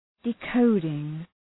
Shkrimi fonetik {dı’kəʋdıŋ}